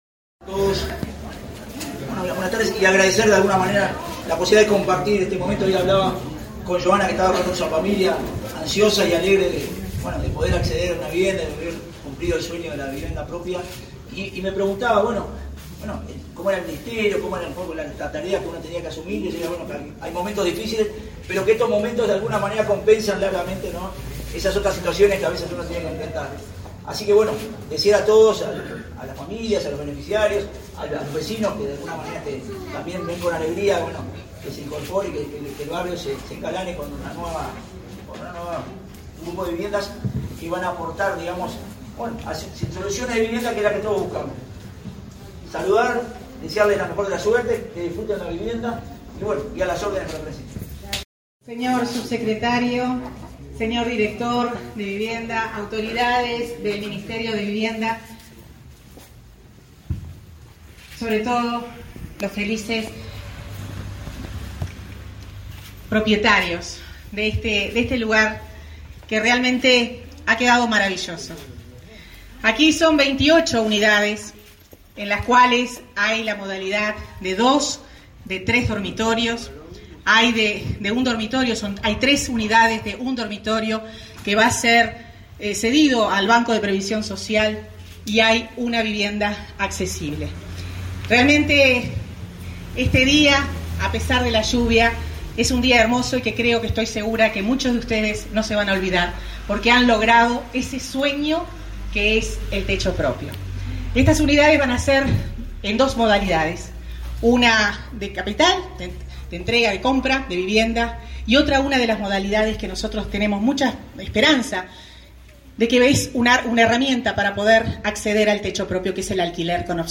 Conferencia de prensa por la entrega de viviendas en Montes y Las Piedras
Participaron la ministra Irene Moreira, y el director nacional de Vivienda, Jorge Ceretta.